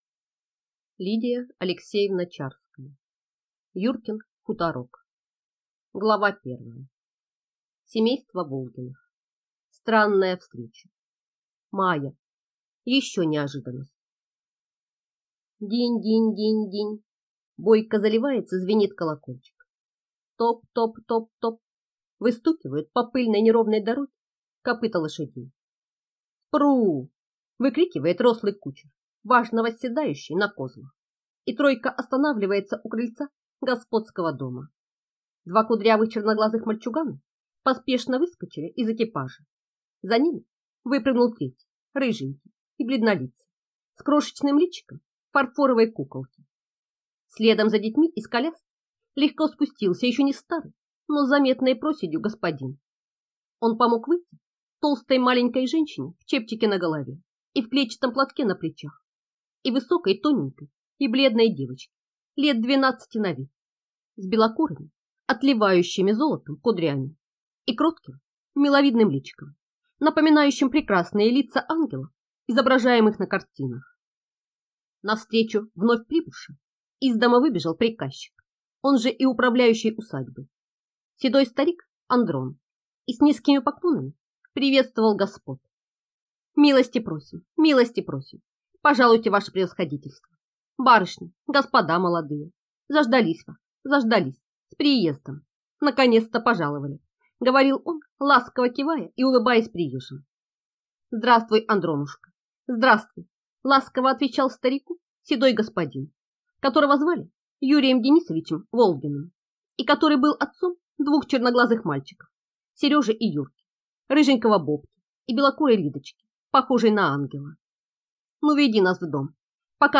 Аудиокнига Юркин хуторок | Библиотека аудиокниг